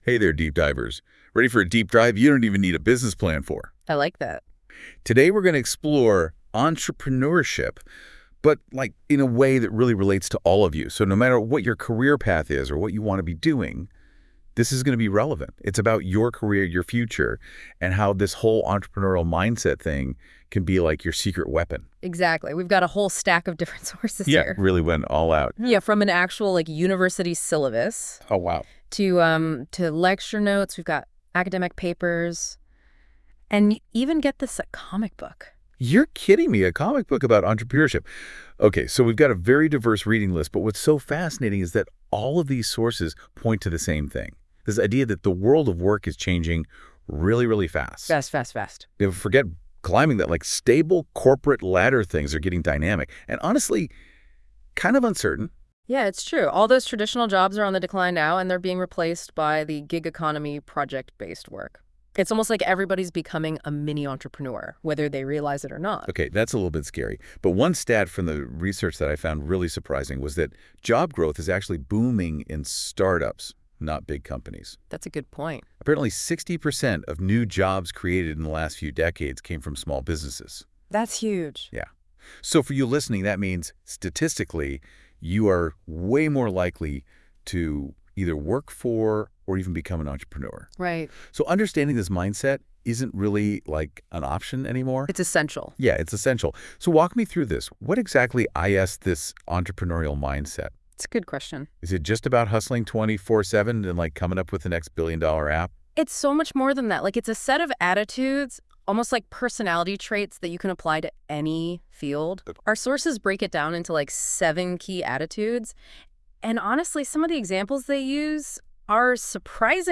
Dive into the world of entrepreneurship like never before with this AI-generated Talk Show about ENT 101: Building an Entrepreneurial Mindset!
Ep-1-AI-Talk-Show-about-ENT-101.wav